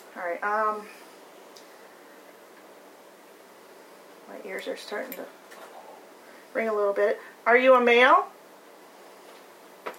EVPs Captured during Paranormal Investigation
StLouis-My-ears-are-VOICE-starting-to-ringwav.wav